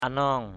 /a-nɔ:ŋ/